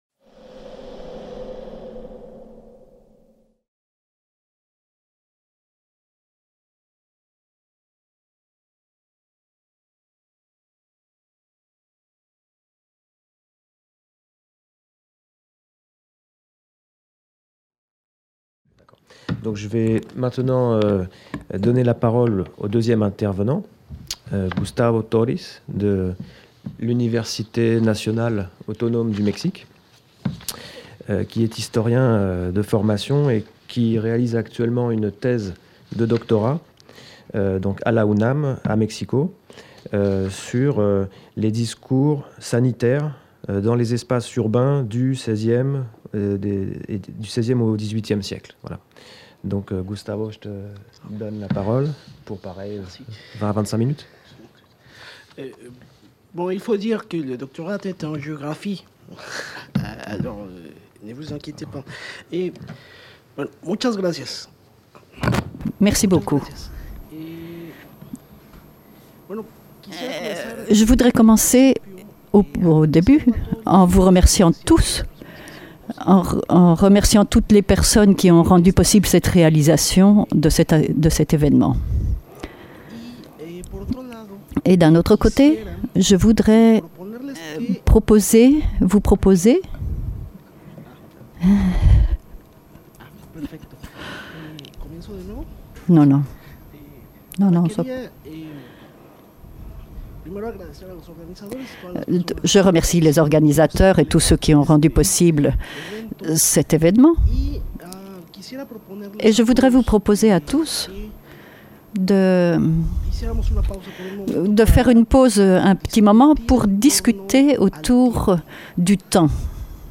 Cette communication a été filmée lors du colloque international "Le droit à Lefebvre" qui s'est déroulé du 29 au 31 mai 2018 à Caen.